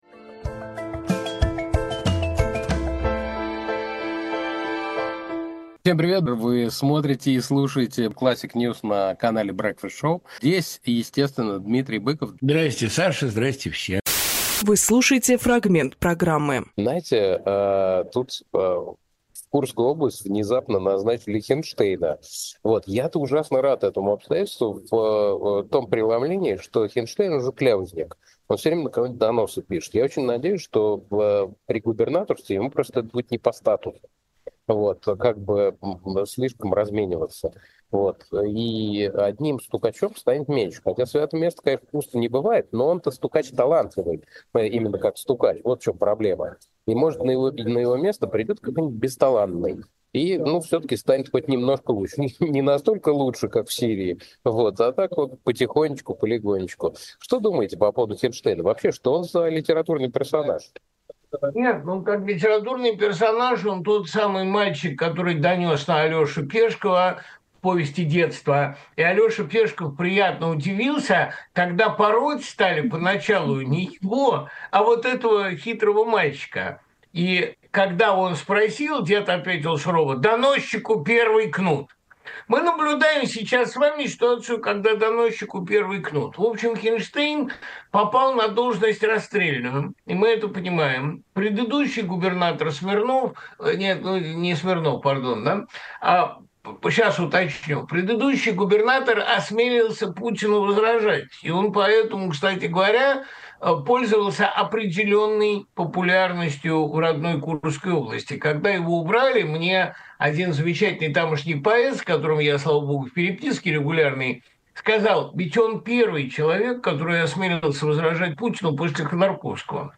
Дмитрий Быковпоэт, писатель, журналист
Александр Плющевжурналист
Фрагмент эфира от 08.12.24